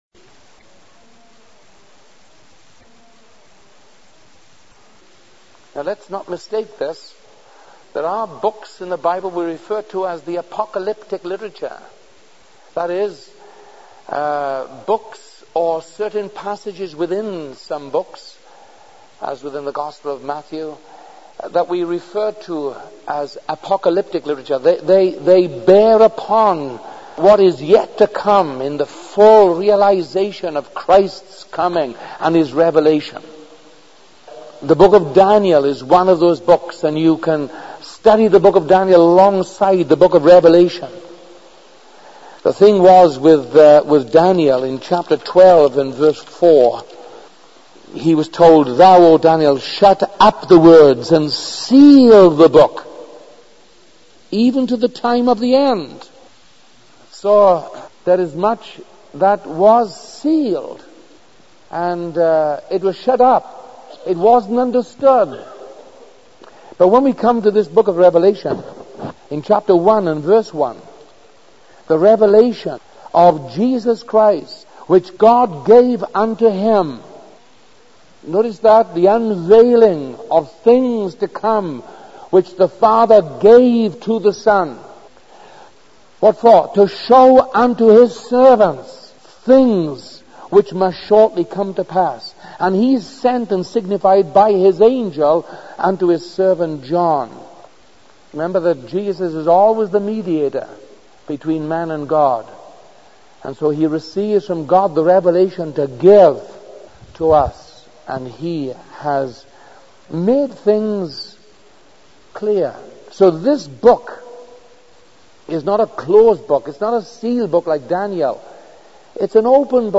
In this sermon, the preacher emphasizes the importance of focusing on the eternal purposes of God as the only lasting reality in life.